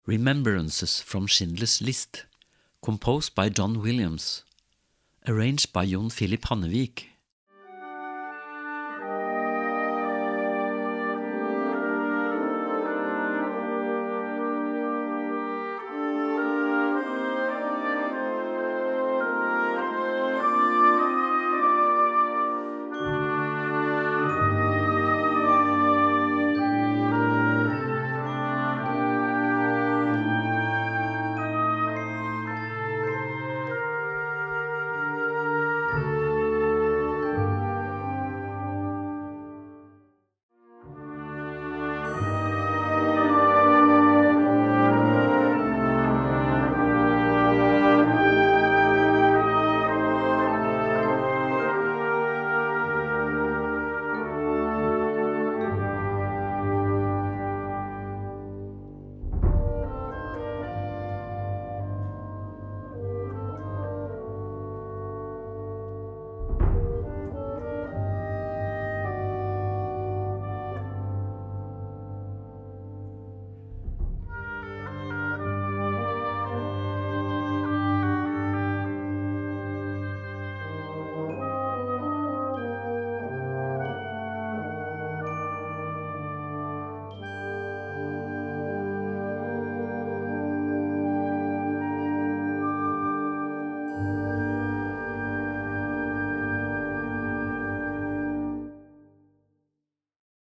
Gattung: Jugendwerk
Besetzung: Blasorchester